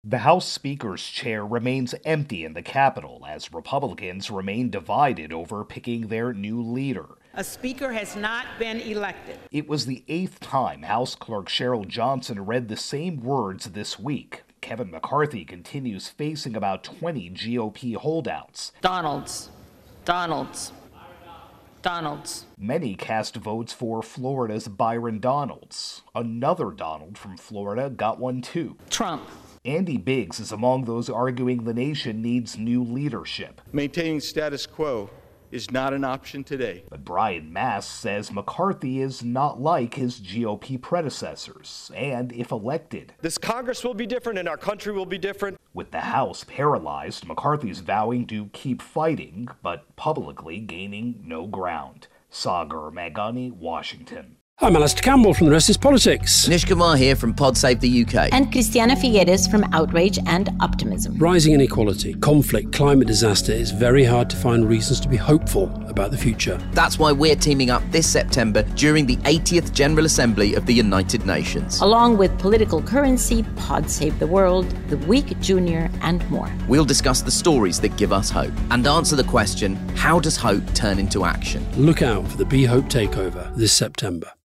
reports on Congress